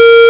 beep_01.wav